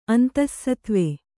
♪ antassatve